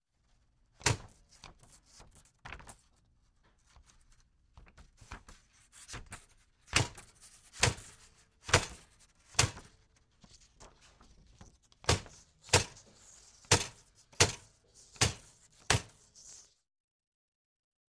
Звуки степлера
Человек психует и лупит по степлеру кулаком